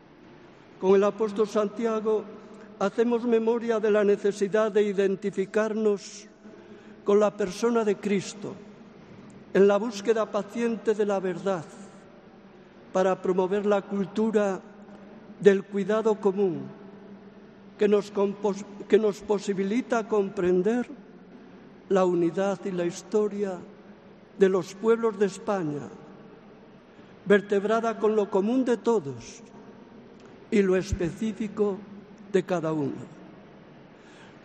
Mensaje del Arzobispo de Santiago en la Ofrenda al Apóstol